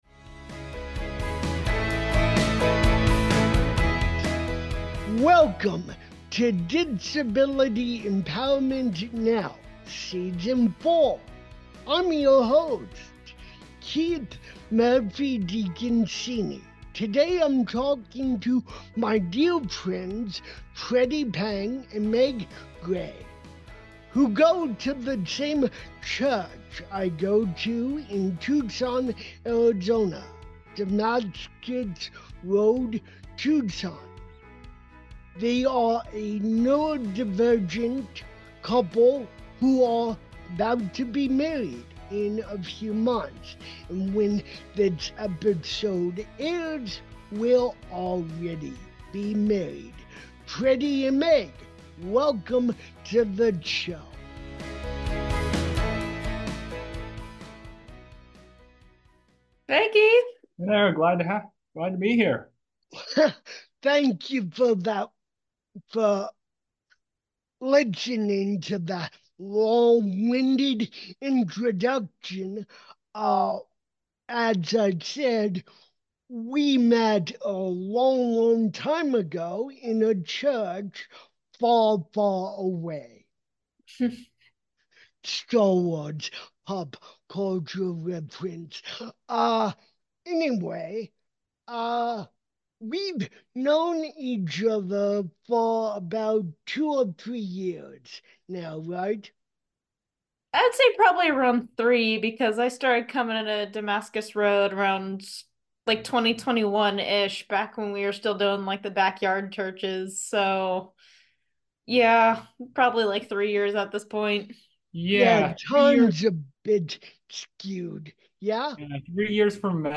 Faith, Dance, and Difference: A Conversation